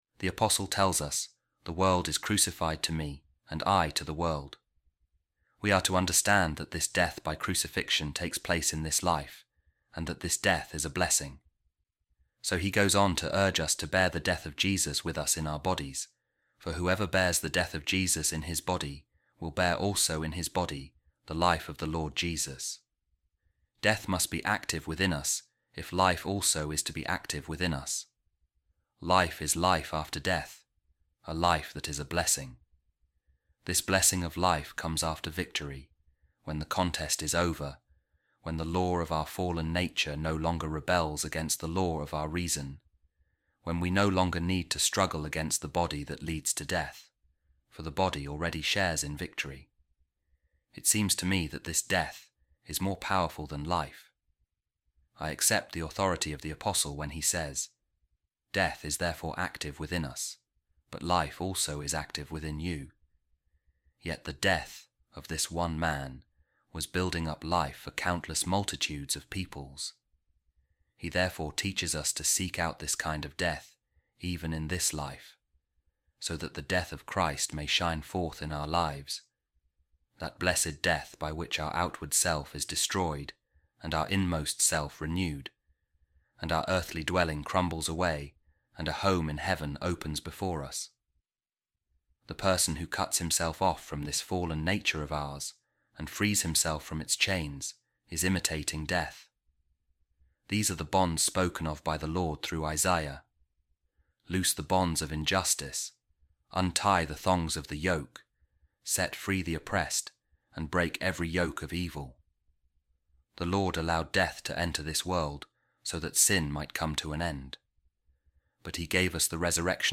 A Reading From The Treatise Of Saint Ambrose On The Blessing Of Death | Let Us Carry The Death Of Jesus In Our Bodies